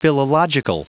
Prononciation du mot philological en anglais (fichier audio)
Prononciation du mot : philological